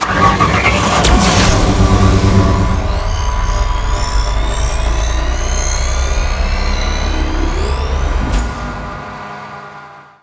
CosmicRageSounds / wav / vehicle / start.wav